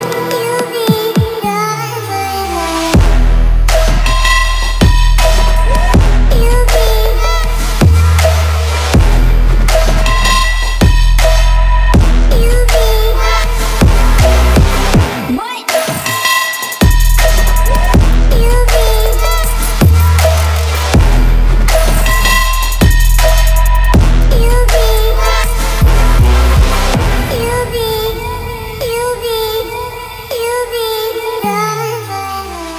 Elektronisk musik, Android